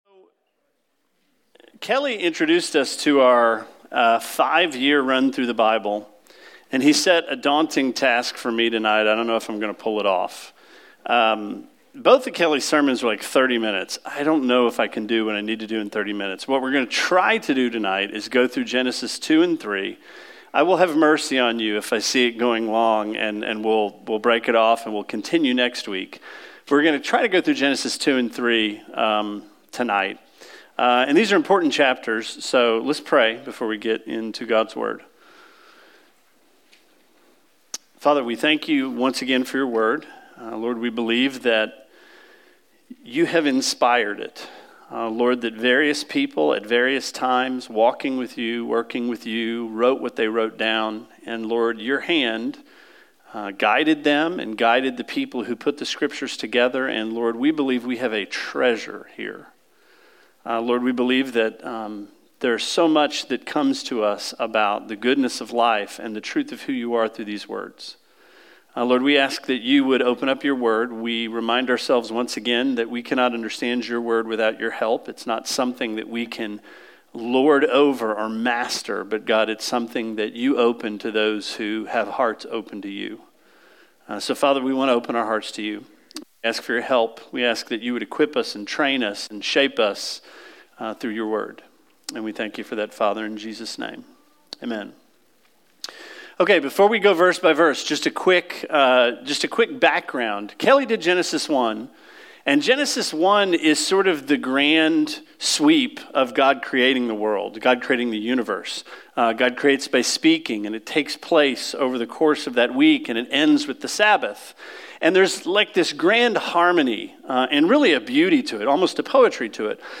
Sermon 03/15: Genesis 2-3